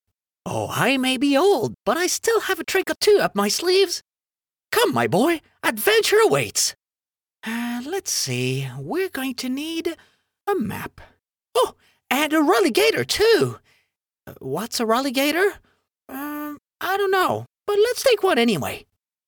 落ち着いた／穏やか
知的／クール
収録　　宅録、
Voice Actor Sample8（年配の男性）[↓DOWNLOAD]